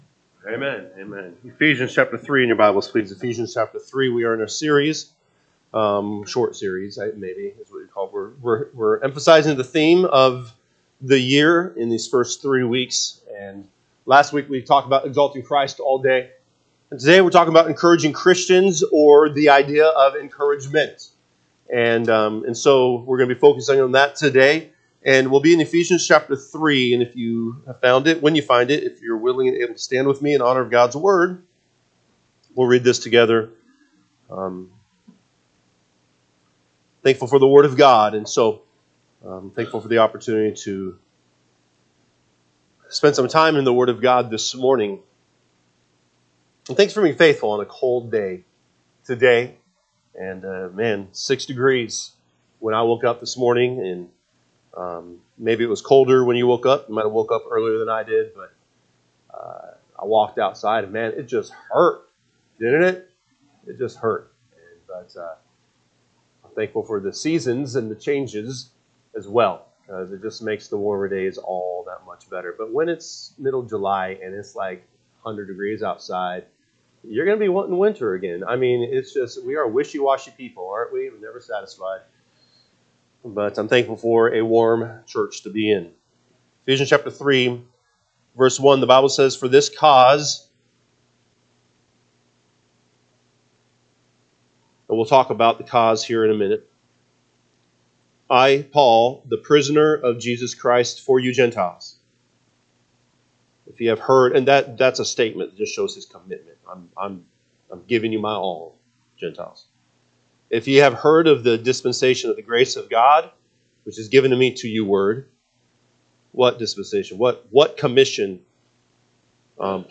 January 19, 2025 am Service Ephesians 3 (KJB) 3 For this cause I Paul, the prisoner of Jesus Christ for you Gentiles, 2 If ye have heard of the dispensation of the grace of God which is g…